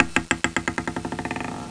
1 channel
tab_ball.mp3